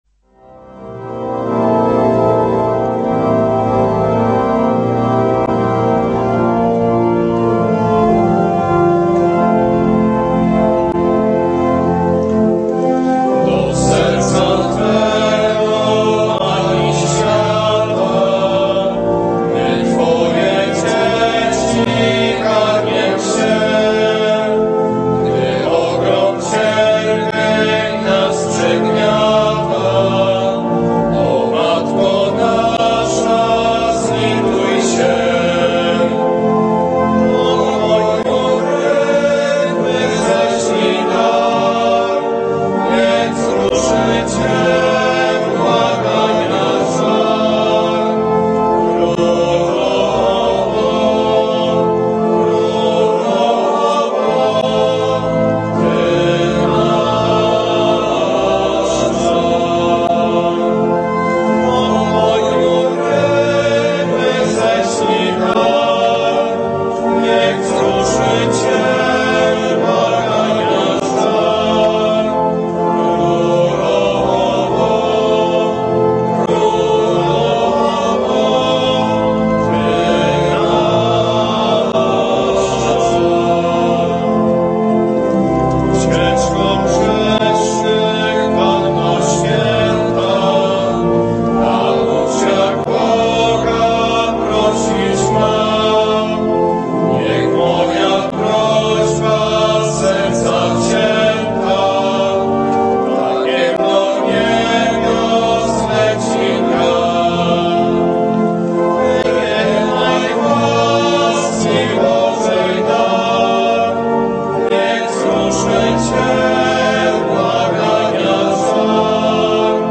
Msza św. - homilia ks.